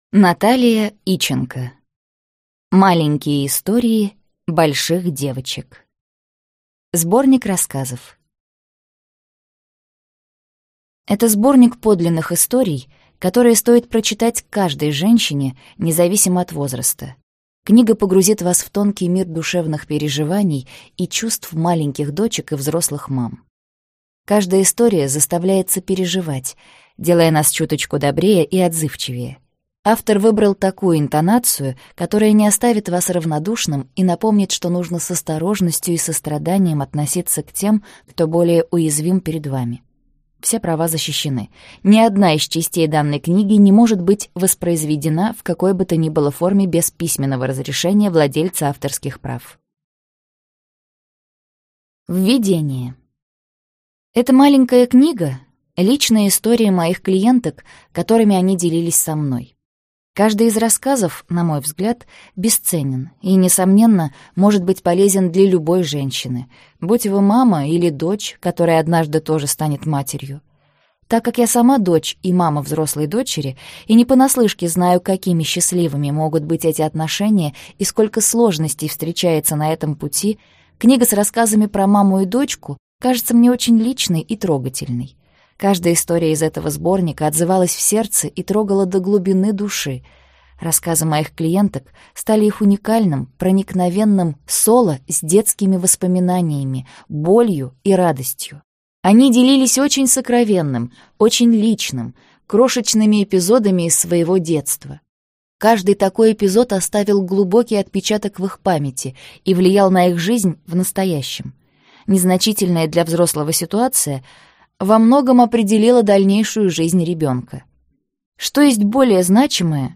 Аудиокнига Маленькие истории больших девочек | Библиотека аудиокниг